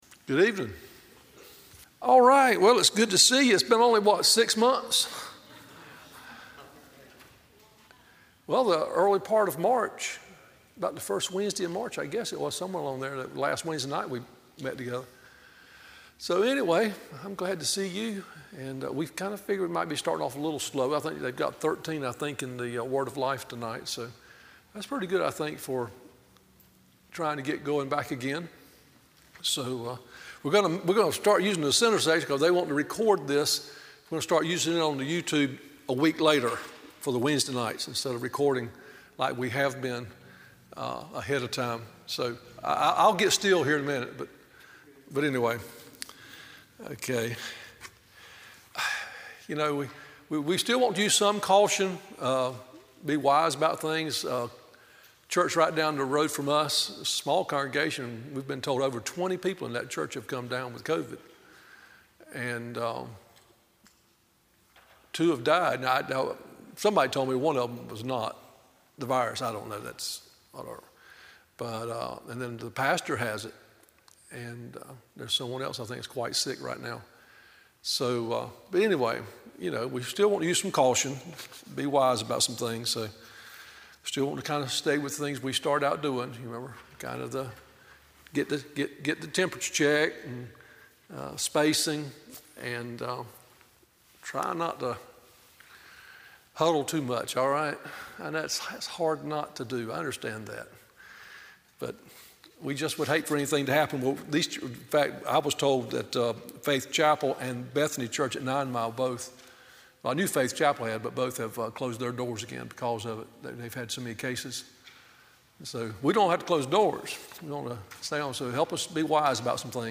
Wednesday Evening Bible Study - Tar Landing Baptist Church